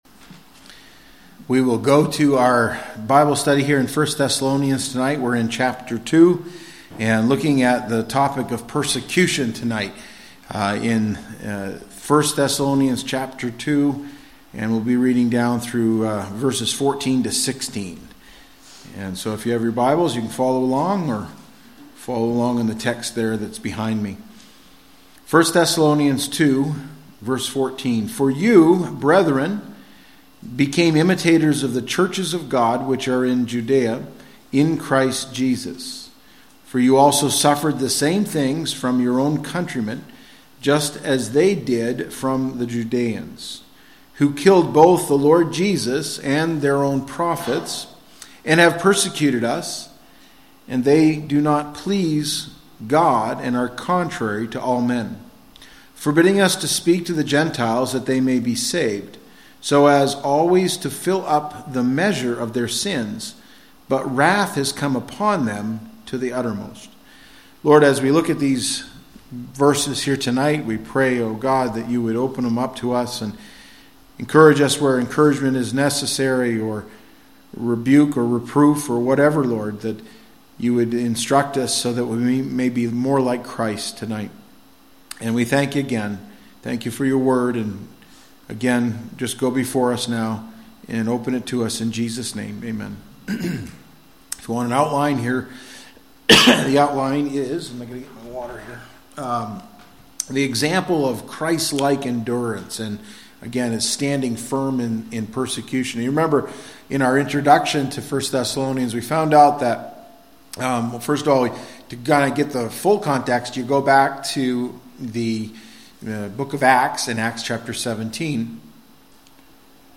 August 24, 2025 - PM Service - 1 Thessalonians 2:14-16